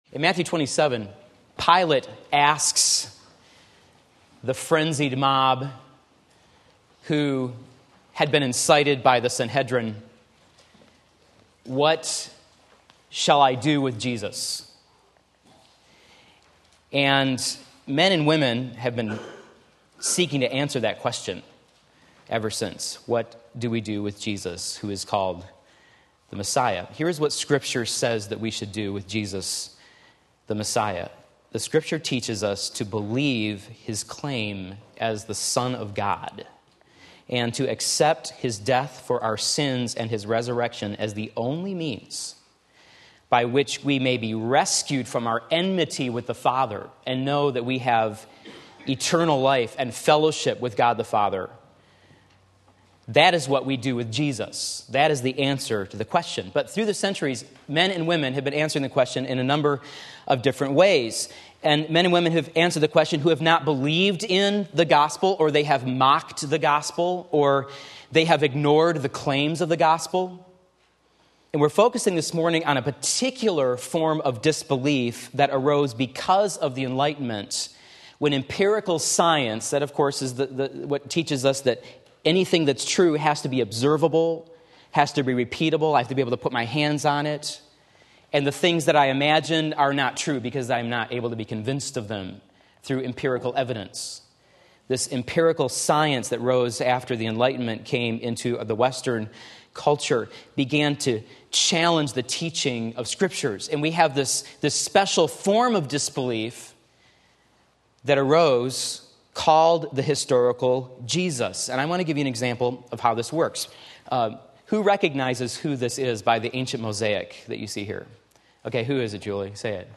Sermon Link
Sunday School